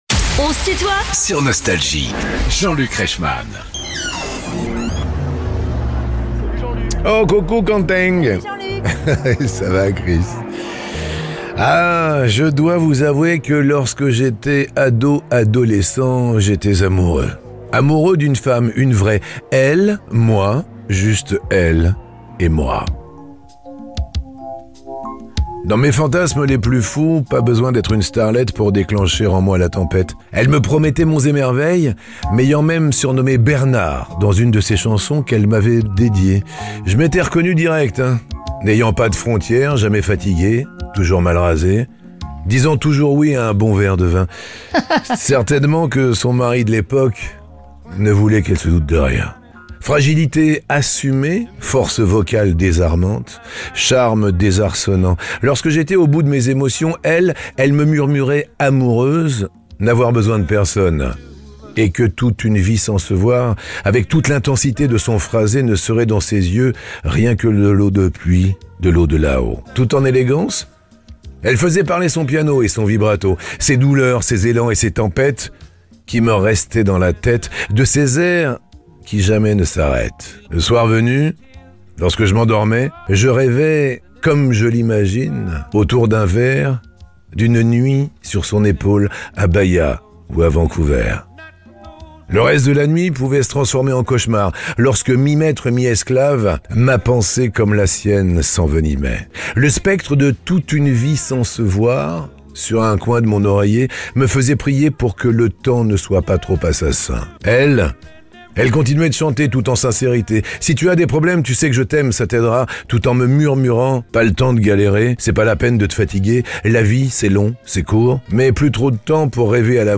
Véronique Sanson est l'invitée de "On se tutoie ?..." avec Jean-Luc Reichmann (partie 1) ~ Les interviews Podcast